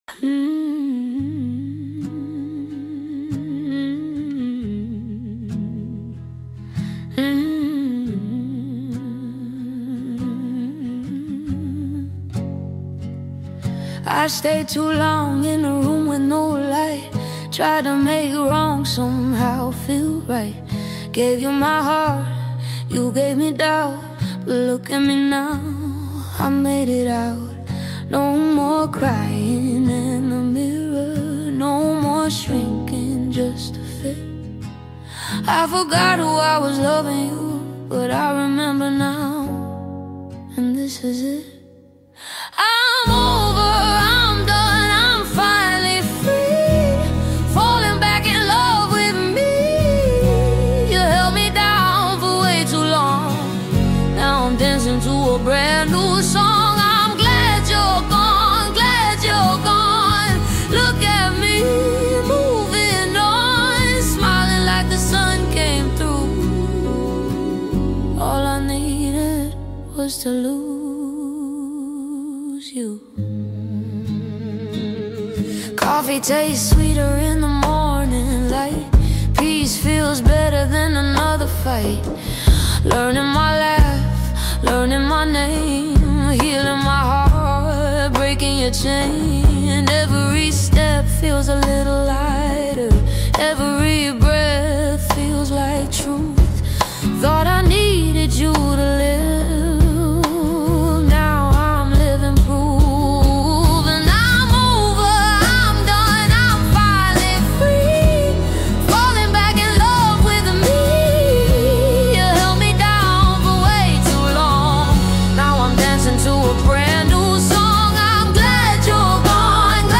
a reflective record that turns past pain into quiet relief.